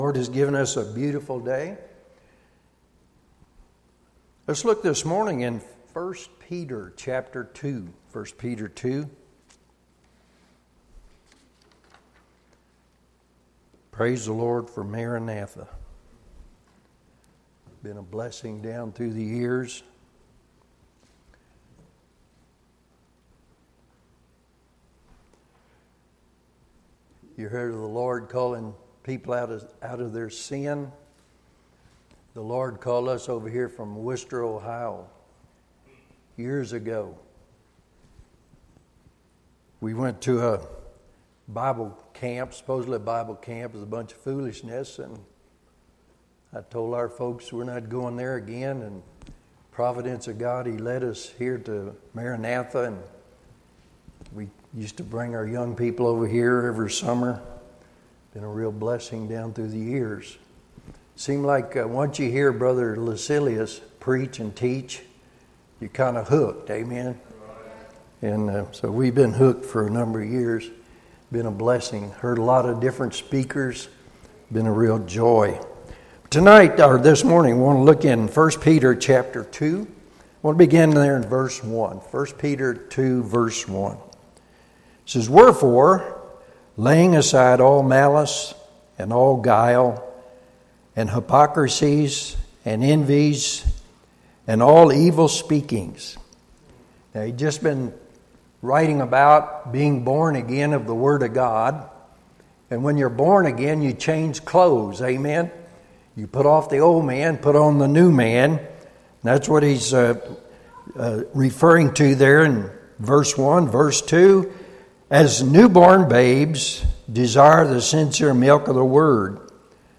Session: Morning Devotion